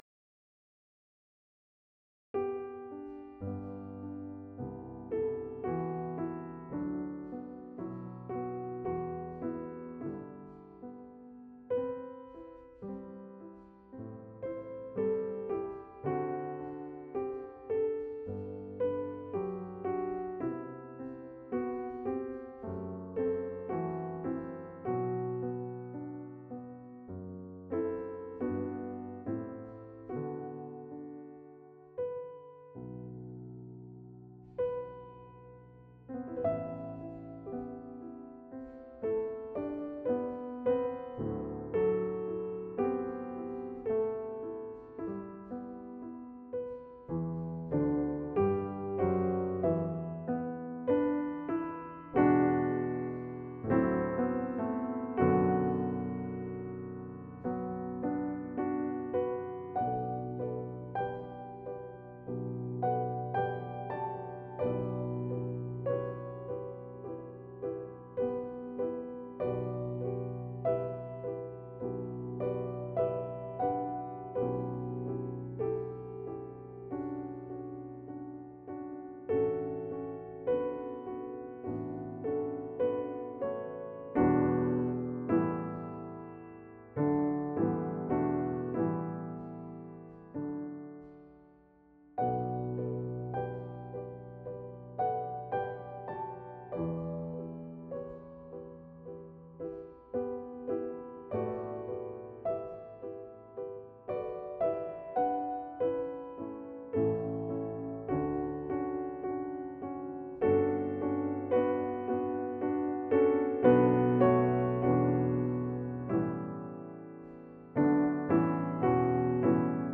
高音や美しすぎる絶妙な和音、そして絶妙な音の推移が印象的なラヴェルのピアノ曲。
ゆったりとした穏やかさと妖艶さが感じられ、思わず目をつぶって瞑想に浸りたくなってしまう美しい１曲でもあります。
後半になるにつれて音に厚みが増し、丸みの中にも重厚感がなんともいえない煌びやかさを華やかにしています。